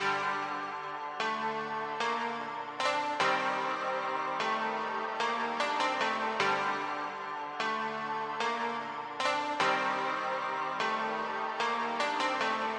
Tag: 150 bpm Trap Loops Piano Loops 1.08 MB wav Key : Unknown